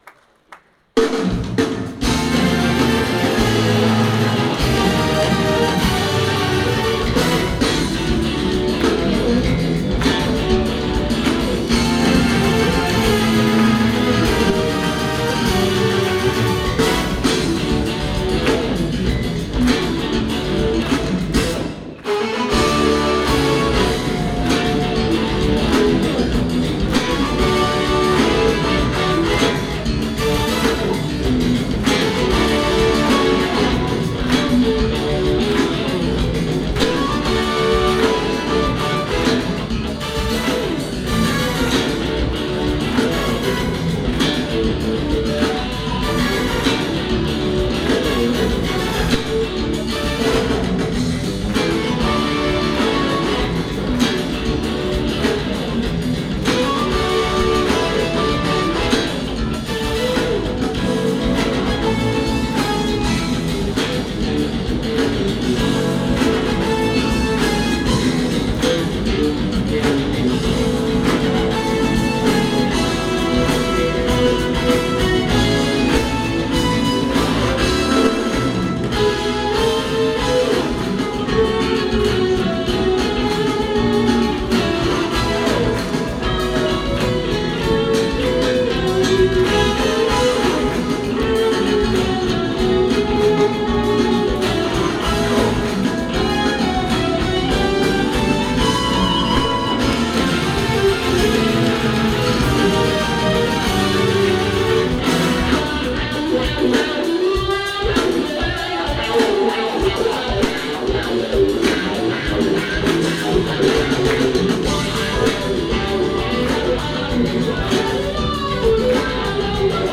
관객이 MD로 녹음한 소스에요.